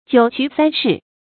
九衢三市 jiǔ qú sān shì
九衢三市发音